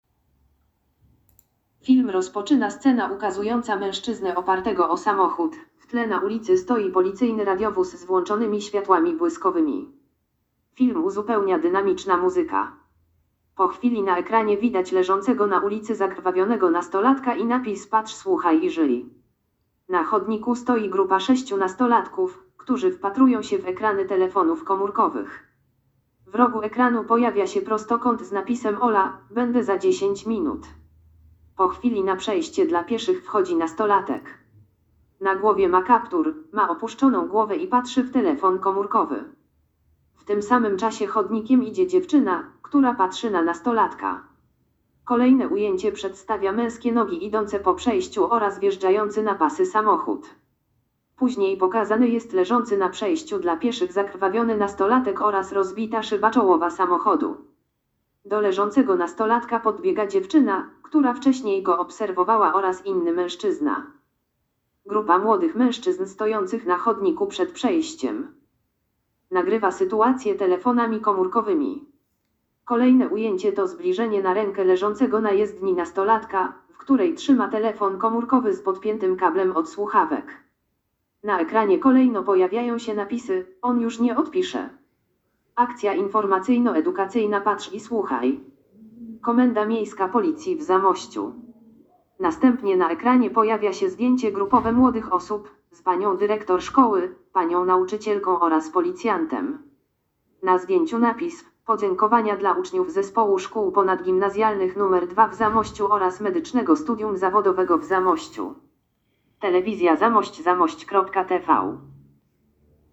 Nagranie audio autodeskrypcja spotu edukacyjnego Patrz Słuchaj i Żyj!